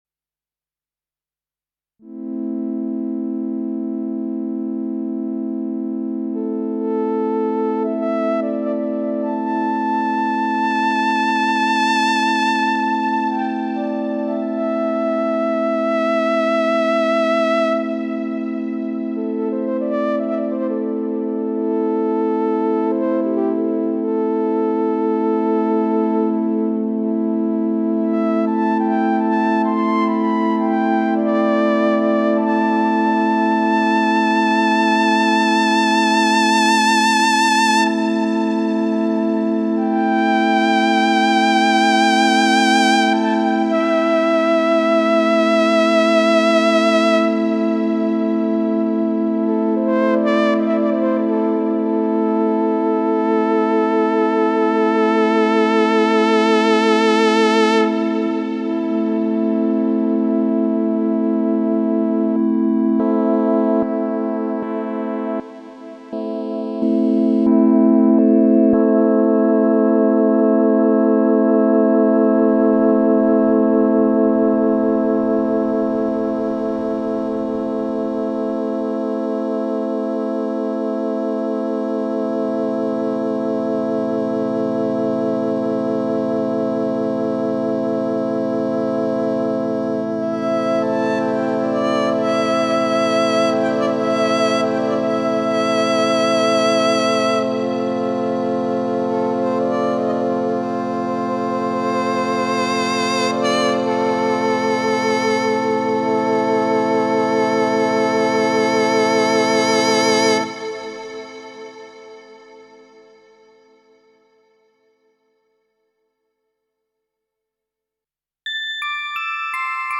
Willkommen in der Welt des ASM Hydrasynth Deluxe, dem wohl am besten ausgestatteten digitalen Synthesizer mit Wavemorphing am Markt.
Im ASM Hydrasynth Deluxe Test ein digitaler Synth, der gewaltige Power unter der Haube hat.
DELAMAR SCORE 4.5 ASM Hydrasynth Deluxe Test Unter dem Strich Virtuell analoger Synthesizer mit Wavemorphing.
Auf diese Weise entstehen sich stets verändernde Soundspektren.
asm_hydrasynth_deluxe_demo.mp3